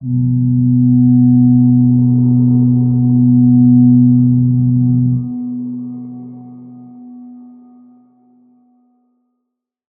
G_Crystal-B3-f.wav